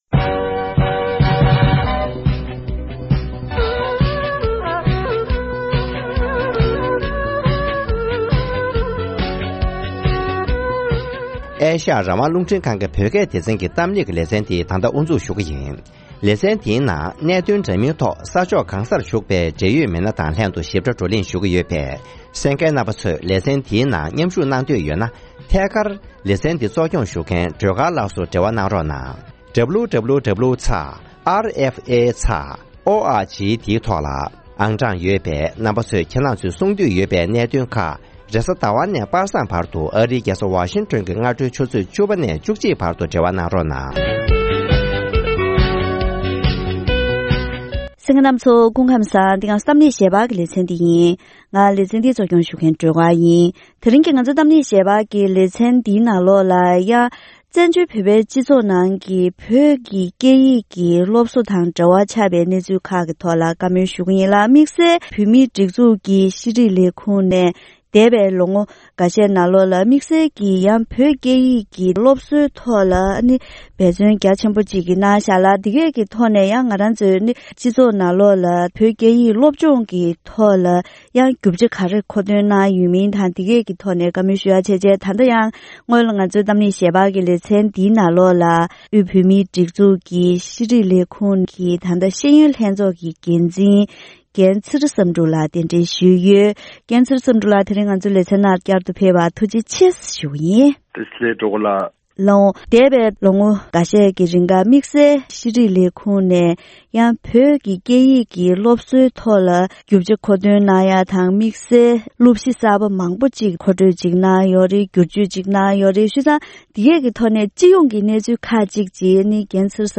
བཙན་བྱོལ་བོད་པའི་སྤྱི་ཚོགས་ནང་བོད་ཀྱི་སྐད་ཡིག་སློབ་གསོའི་སྐོར་དང་བོད་ཡིག་སློབ་དེབ་བསྐྱར་ཞིབ་དང་རྩོམ་སྒྲིག་སློབ་ཁྲིད་སོགས་ཀྱི་སྐོར་ལ་བཅར་འདྲི།